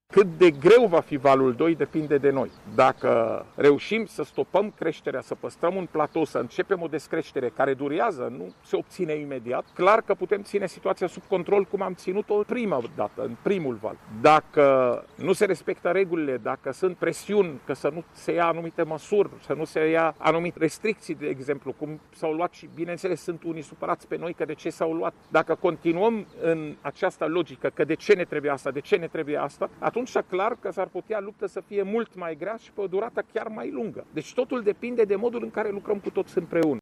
Suntem în valul doi al pandemiei, afirmă şeful Departamentului pentru Situaţii de Urgenţă, Raed Arafat. El a precizat că, potrivit estimărilor specialiştilor, acest val se va prelungi pe toată durata iernii: